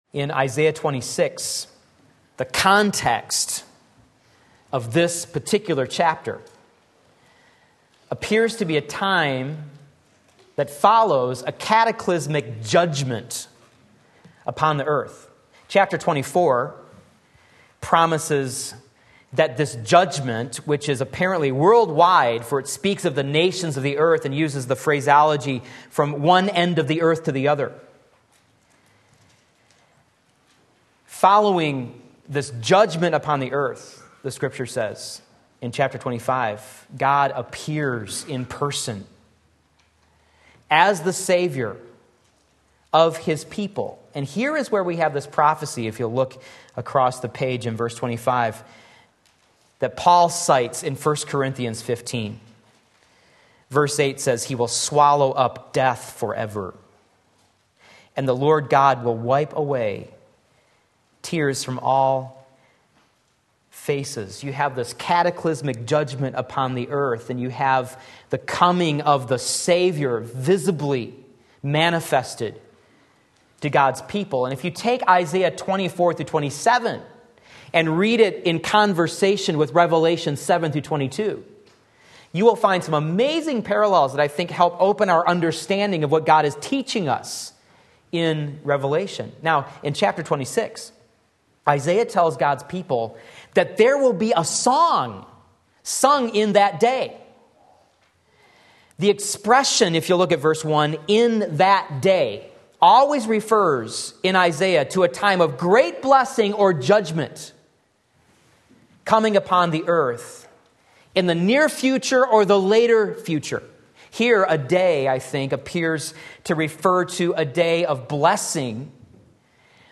Sermon Link
The Shalom Shalom of God Isaiah 26:3 Sunday Morning Service